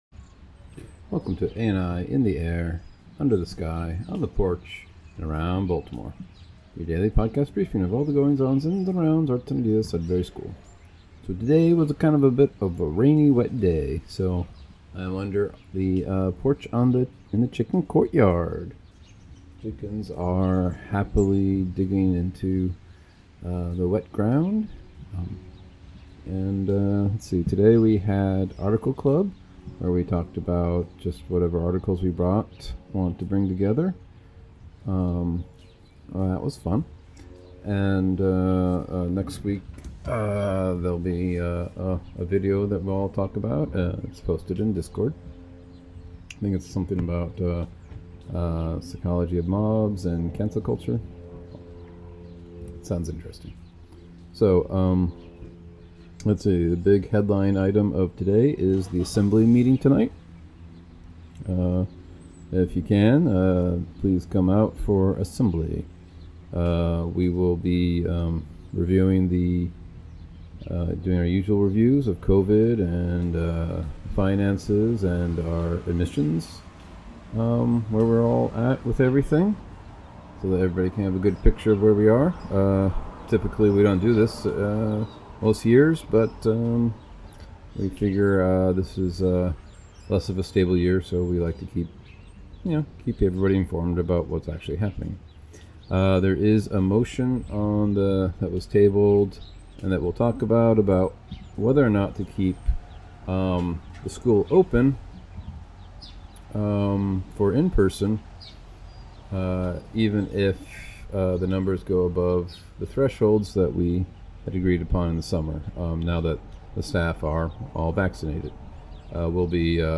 Rainy day, recording from chicken courtyard porch.